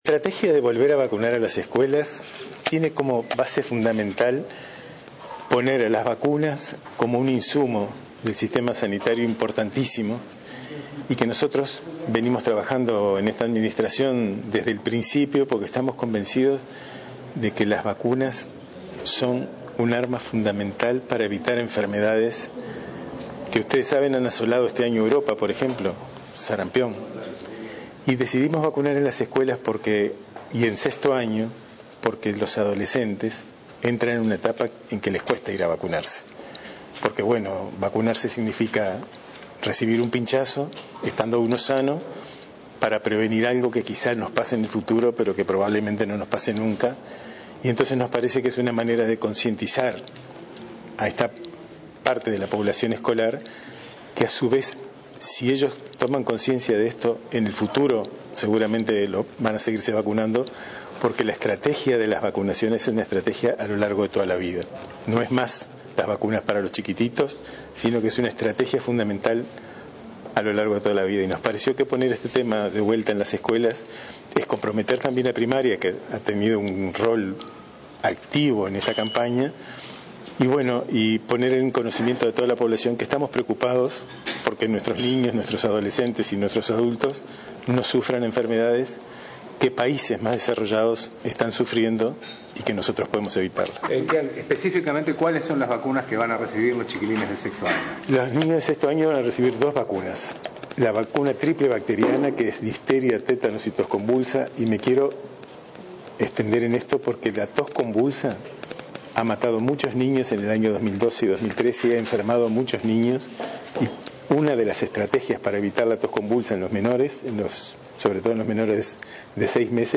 Todos los niños de sexto año recibirán en escuelas la vacuna triple bacteriana (difteria, tétanos y tos convulsa), las niñas, además, las del papiloma humano (HPV). Se inoculará en dos períodos: abril-mayo y setiembre-octubre, informó el subsecretario de Salud Pública, Jorge Quian. Sobre el HPV, dijo que las vacunas disponibles en Uruguay cubren las cepas relacionadas con el 75 % de los cánceres de cuello uterino.